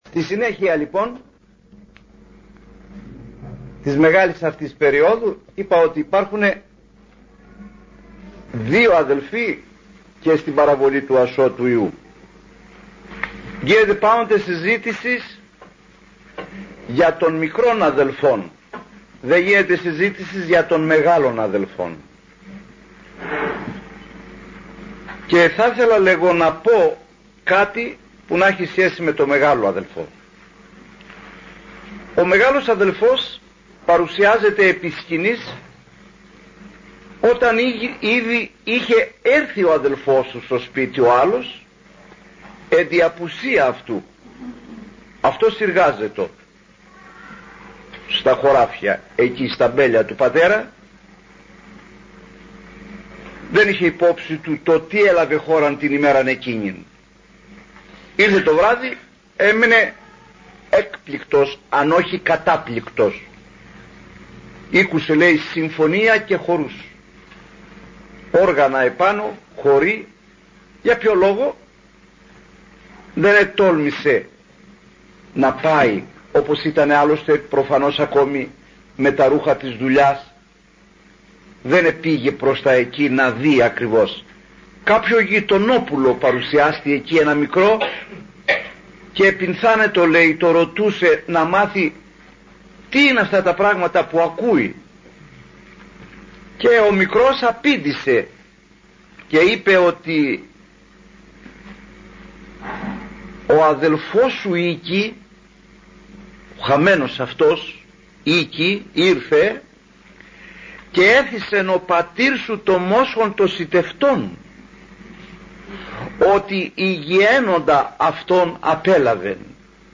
Ακολούθως παραθέτουμε ηχογραφημένη ομιλία του αειμνήστου “λαϊκού” ιεροκήρυκος
Ζητούμε προκαταβολικά συγνώμη από τους ακροατές – επισκέπτες του ιστοχώρου μας για την μάλον κακή ποιότητα του ήχου, αλλά νομίζουμε πως αξίζει τον κόπο να υπερβούμε το μικρό αυτό πρόβλημα, για να πάρουμε την μεγάλη βοήθεια που προσφέρει ο διακεκριμένος αείμνηστος αυτός διάκονος του Ιερού Άμβωνος.